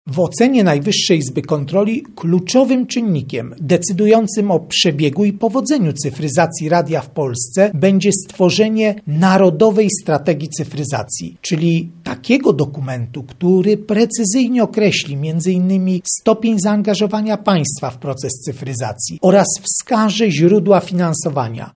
Raport wskazuje, że określenie warunków cyfryzacji, terminów jej wdrażania i źródeł finansowania jest ważne i dla nadawców i dla słuchaczy, mówi Krzysztof Kwiatkowski, prezes NIK.